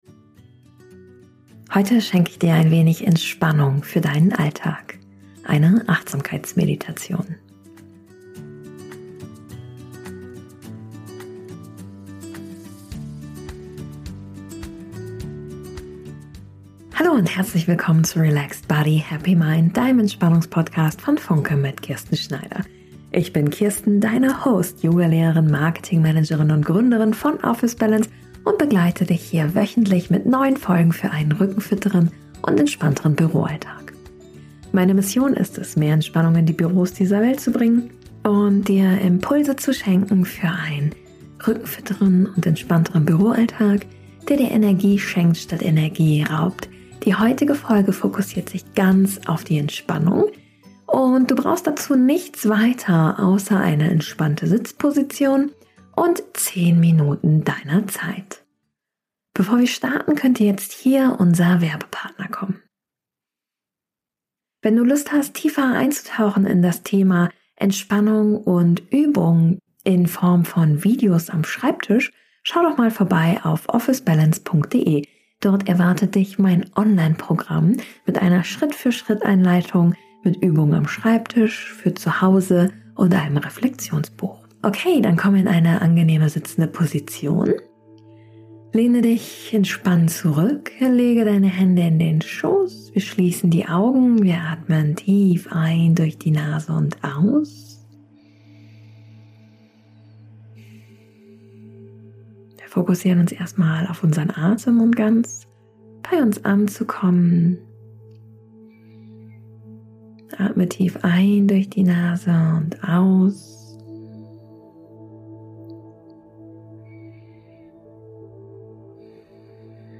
#60 Achtsamkeitsmeditation- 10 Minuten Mini-Pause gegen Kopfschmerzen und Verspannungen ~ Relaxed body, happy mind Podcast